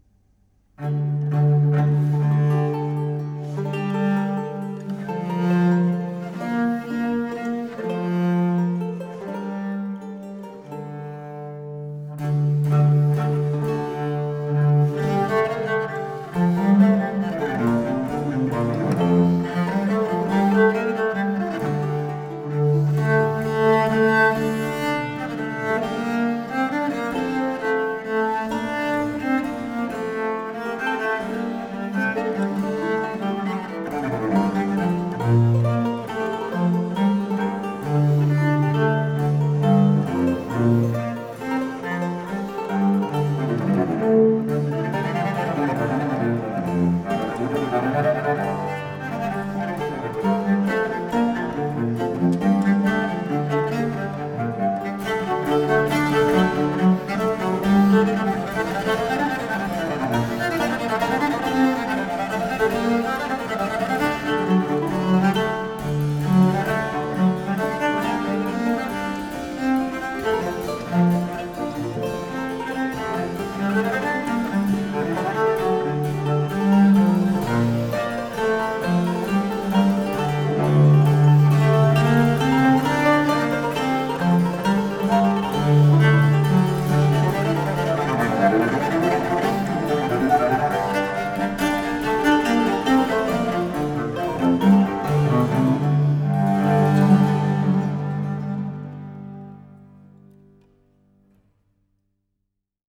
Música tradicional
Orquesta